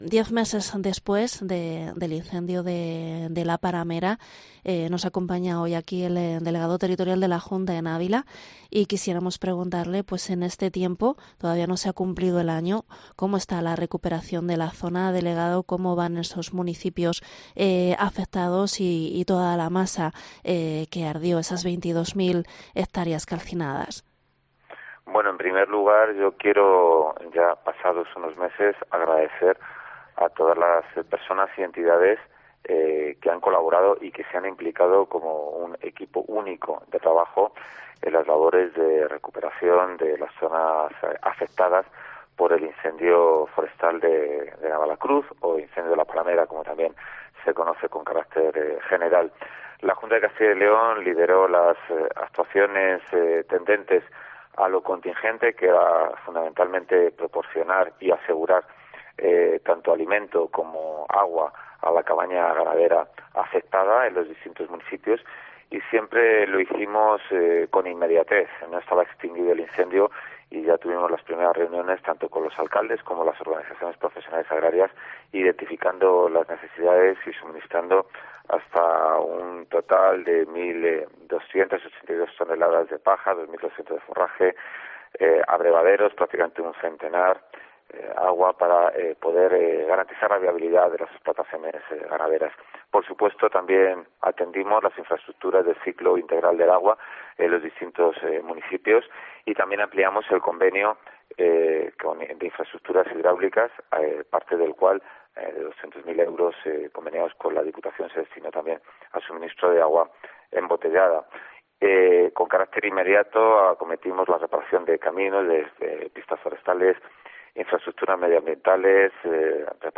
Entrevista delegado territorial incendio de La Paramera